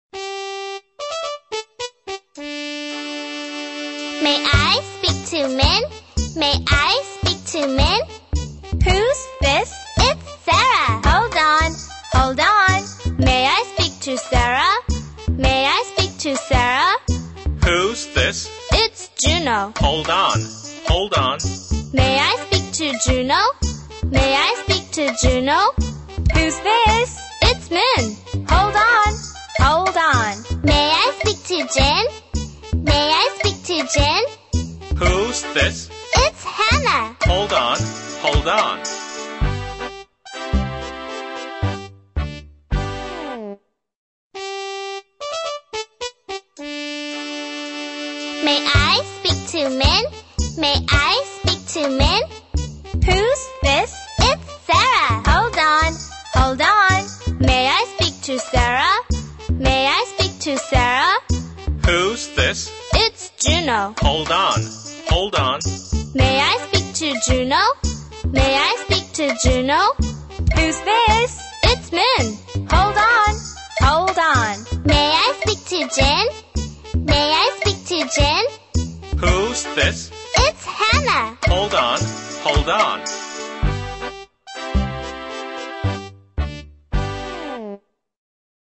在线英语听力室英语儿歌274首 第133期:May I Speak to Min的听力文件下载,收录了274首发音地道纯正，音乐节奏活泼动人的英文儿歌，从小培养对英语的爱好，为以后萌娃学习更多的英语知识，打下坚实的基础。